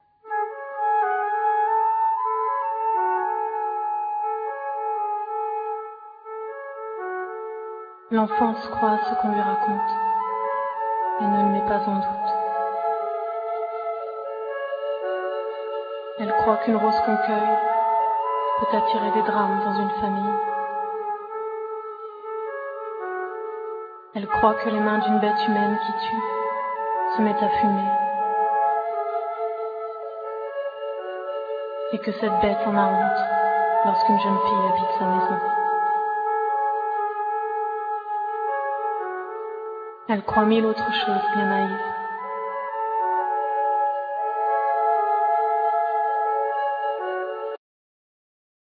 Keyboards, sing, percussions, flutes
Percussions,narration
Violin